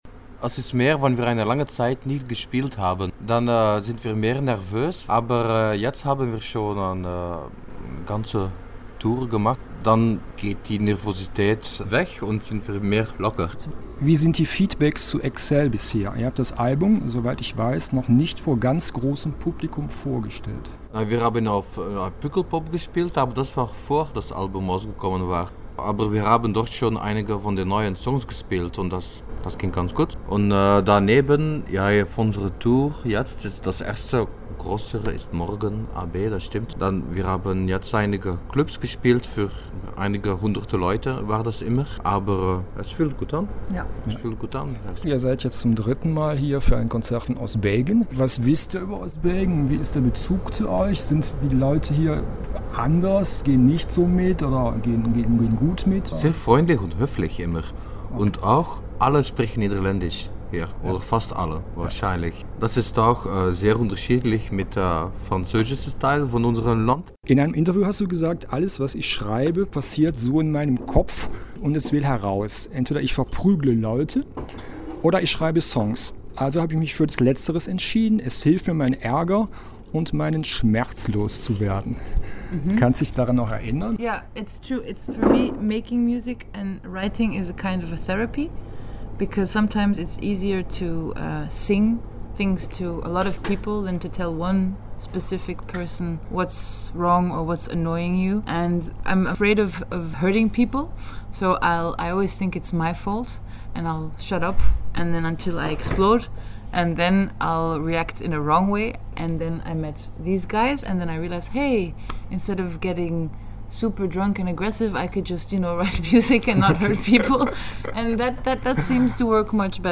Band-Talk mit den Intergalactic Lovers
Die Intergalactic Lovers haben am Mittwochabend das Kulturzentrum Alter Schlachthof in Eupen gerockt!